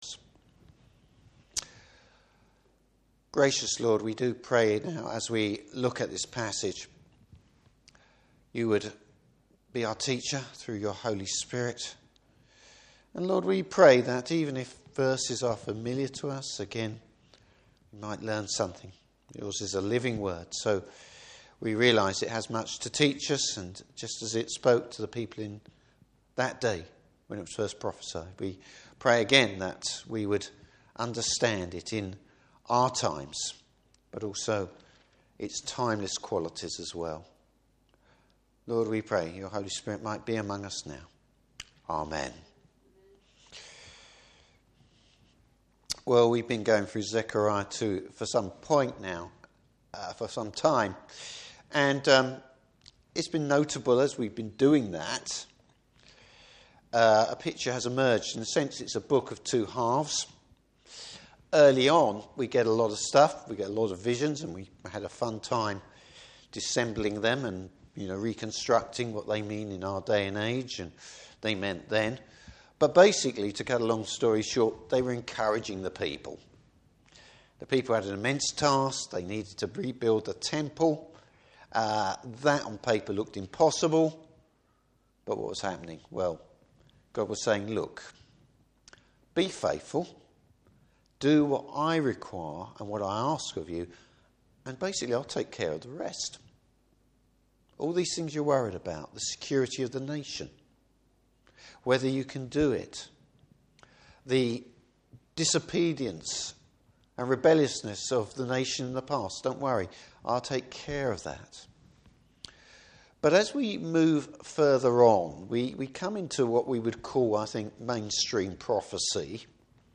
Service Type: Evening Service After mourning for their sin, God’s chosen people experience his forgiveness.